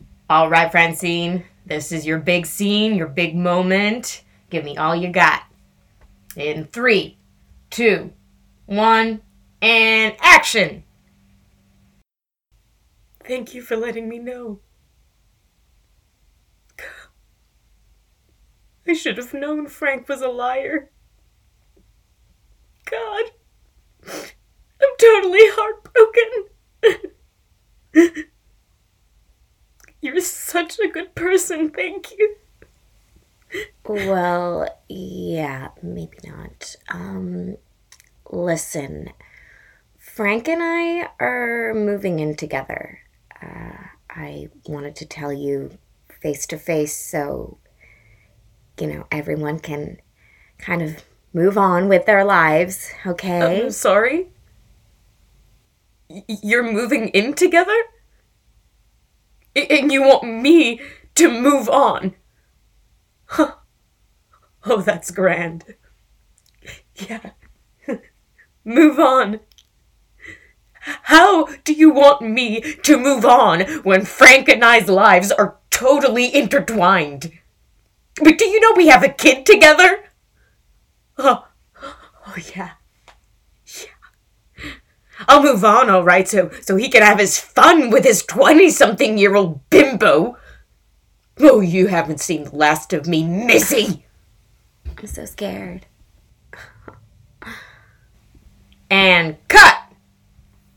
Voix personnages